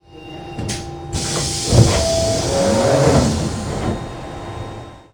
doorsopen.ogg